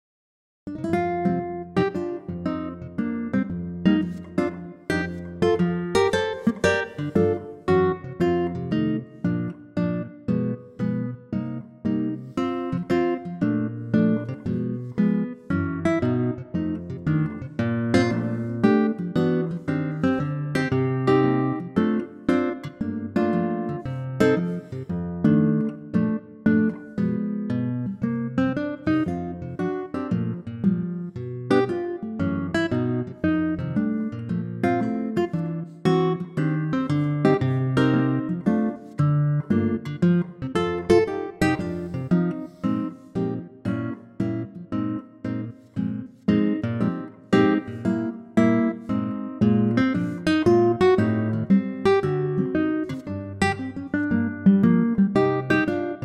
key - Bb - vocal range - Db to F
Superb acoustic guitar arrangement